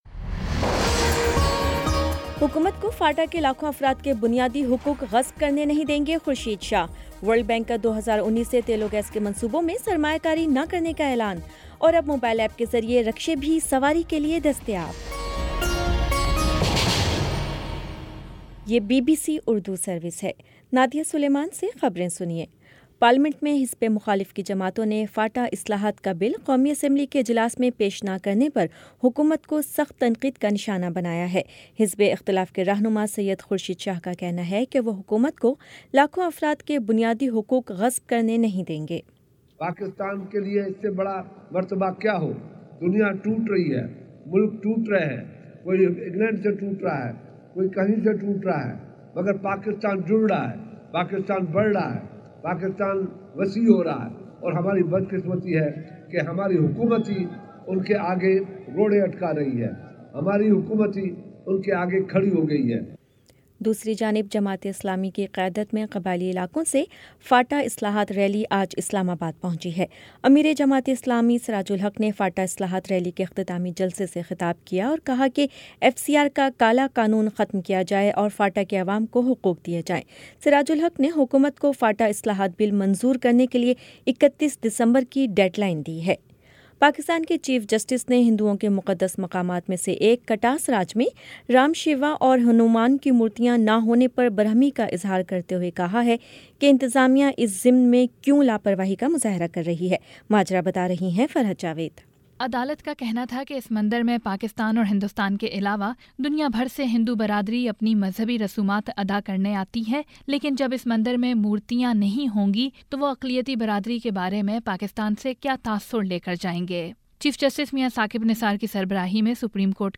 دسمبر 12 : شام سات بجے کا نیوز بُلیٹن